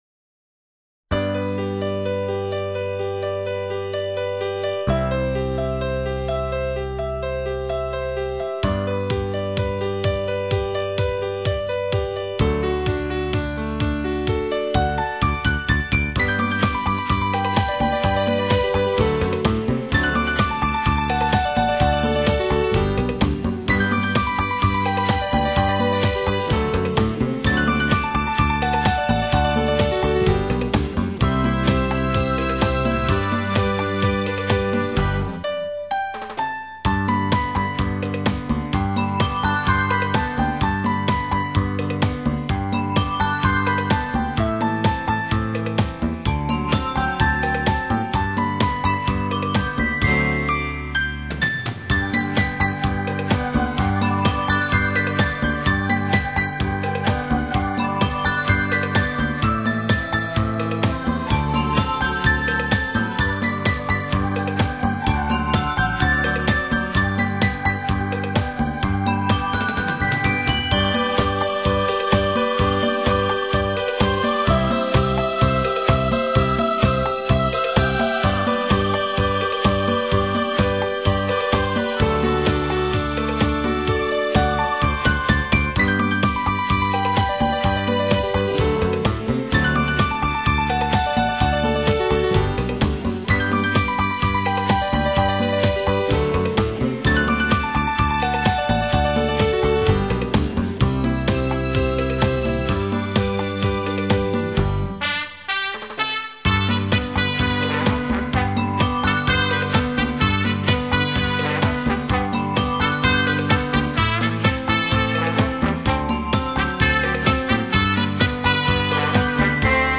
美妙的旋律 轻盈的舞步 飘洒着我们的风采